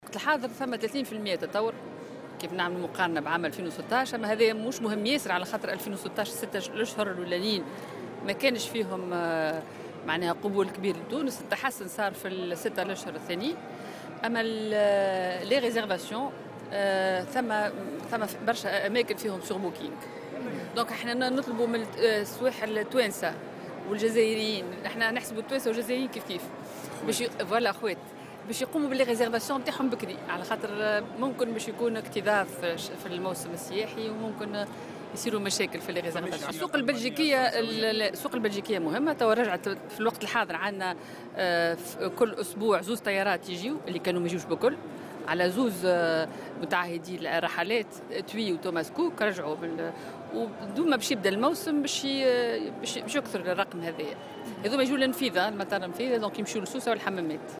وأكدت اللومي في تصريح ل"الجوهرة أف أم" على هامش زيارتها إلى الصالون الدولي للسياحة بفضاء معرض سوسة الدولي أن الحجوزات سجلت منذ انطلاق الموسم ارتفاعا بـ30 بالمائة مقارنة بنفس الفترة من السنة الماضية، مشيرة إلى أهمية عودة السوق البلجيكية من خلال وصول رحلتين أسبوعيا عبر مطار النفيضة.